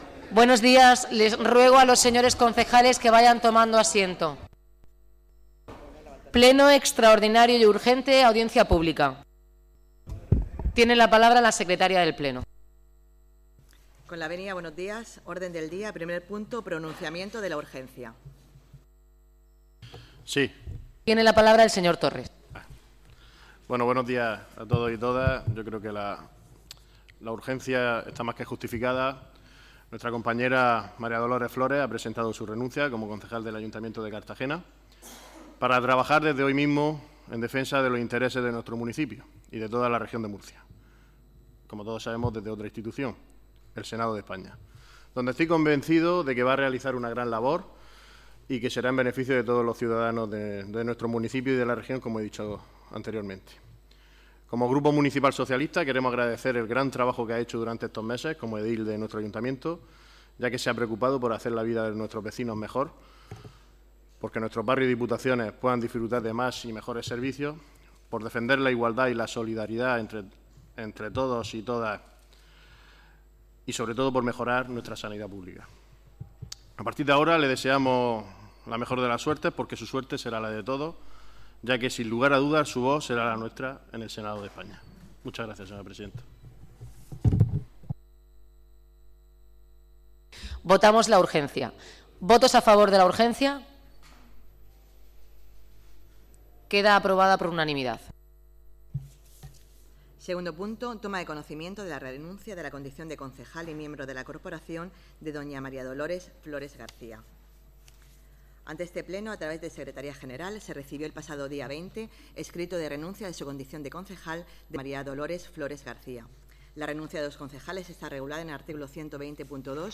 El pleno de la corporación municipal del Ayuntamiento de Cartagena se ha reunido este lunes 23 de octubre en sesión extraordinaria y urgente, bajo la presidencia de la alcaldesa, Noelia Arroyo, para tomar conocimiento de la renuncia de María Dolores Flores como concejal de la corporación.